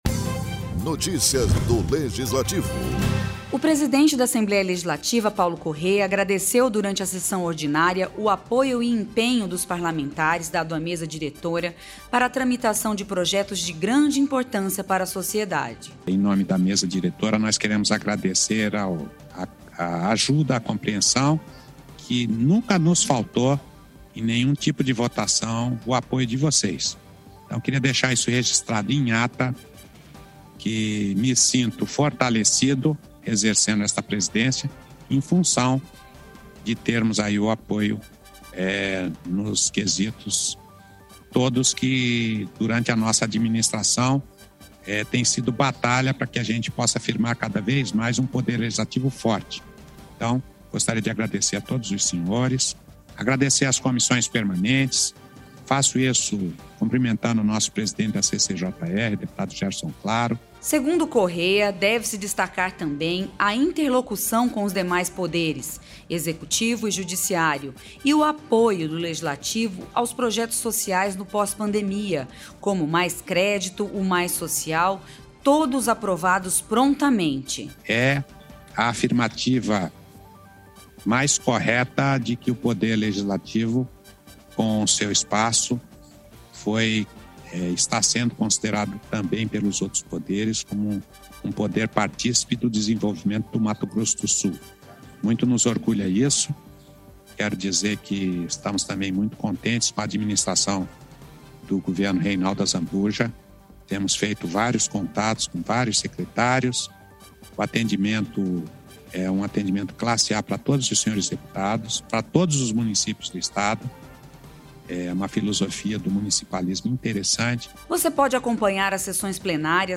Durante a sessão ordinária, o presidente da Assembleia Legislativa Paulo Corrêa, do PSDB, agradeceu o empenho dos parlamentares dado à Mesa Diretora para tramitação de projetos de grande importância para a sociedade.